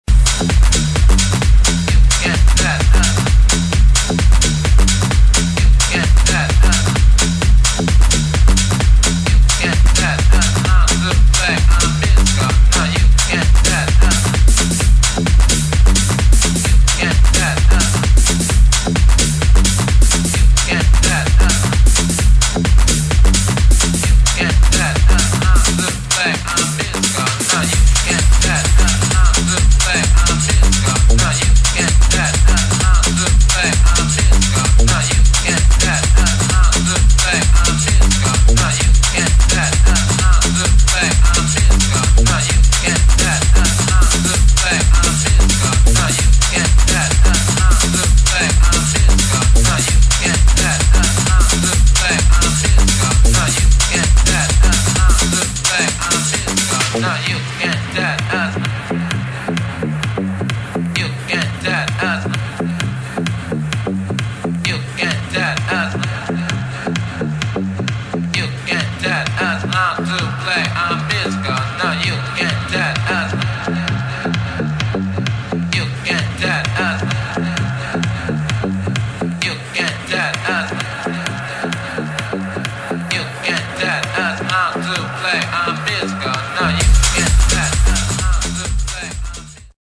[ TECHNO / HOUSE ]